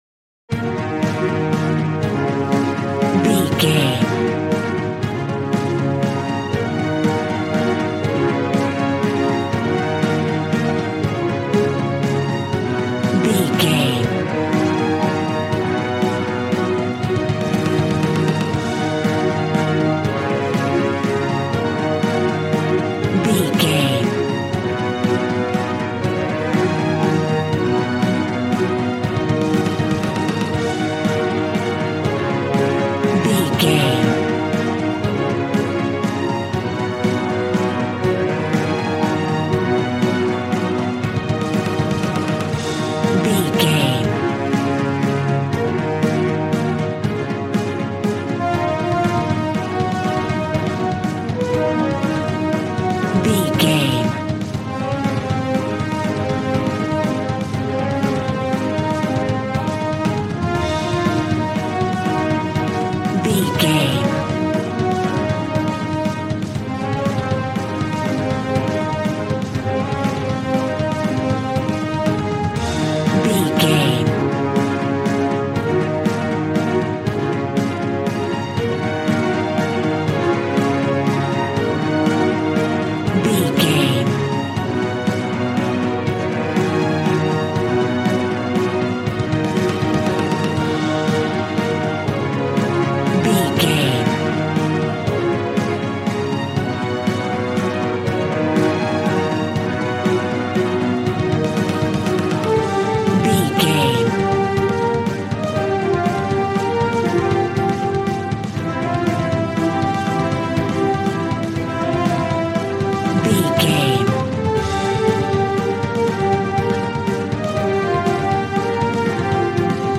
Aeolian/Minor
E♭
dramatic
epic
strings
violin
brass